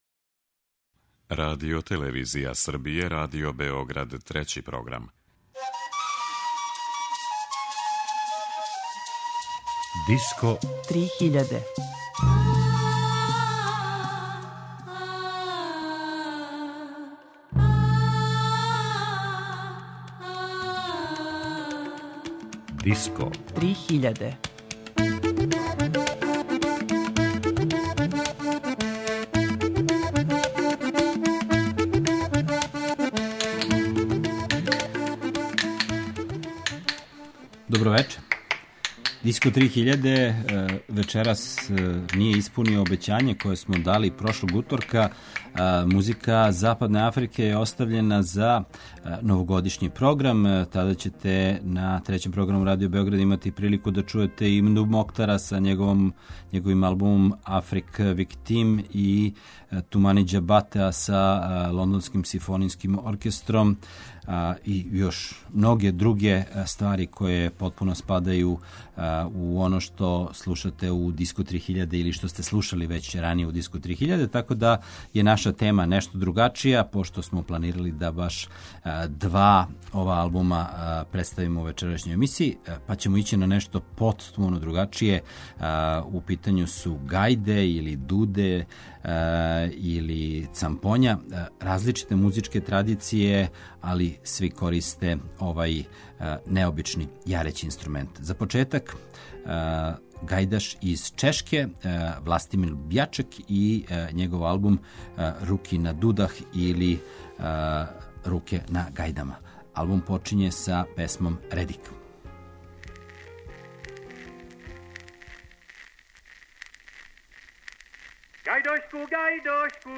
За крај године – гајде
Гајде, дуде, цампоња, богата је традиција.